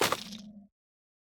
Minecraft Version Minecraft Version 25w18a Latest Release | Latest Snapshot 25w18a / assets / minecraft / sounds / block / sculk_vein / break4.ogg Compare With Compare With Latest Release | Latest Snapshot